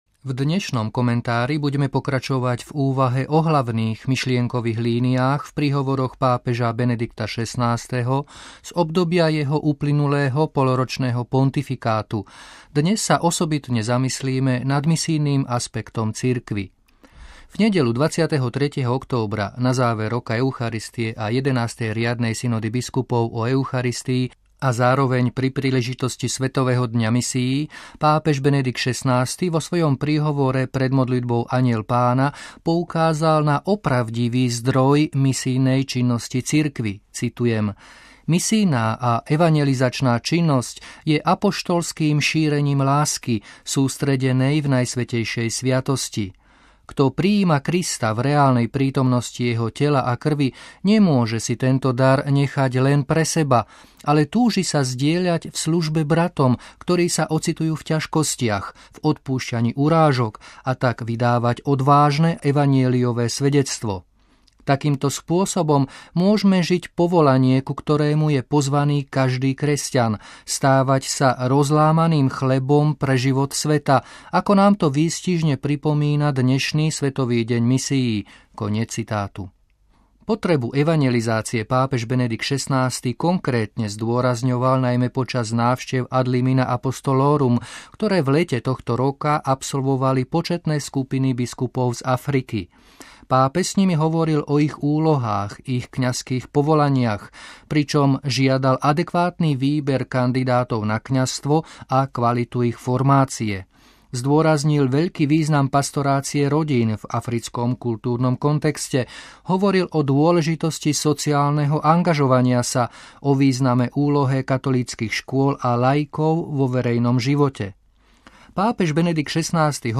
Komentár: Misijný aspekt cirkvi v príhovoroch Benedikta XVI.